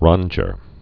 (rŏnjər, -dyr)